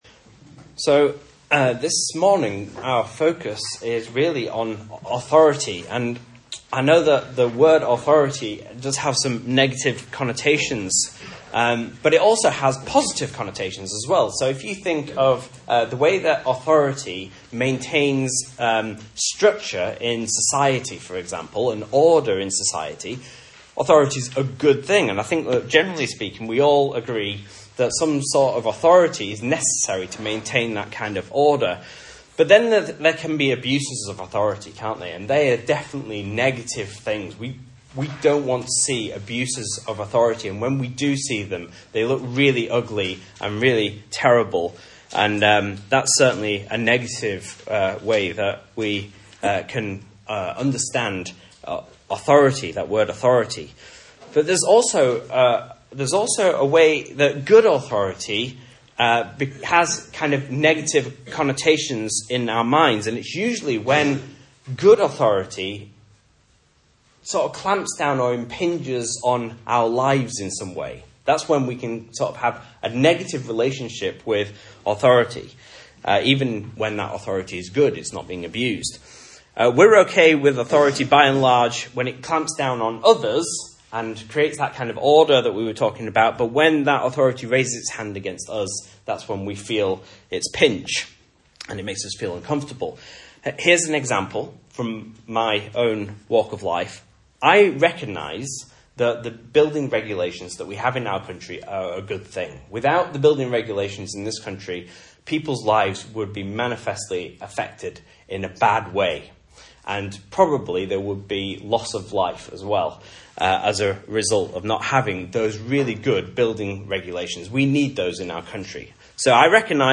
Message Scripture: Mark 11:27-12:34 | Listen